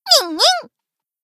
BA_V_Izuna_Swimsuit_Battle_Shout_3.ogg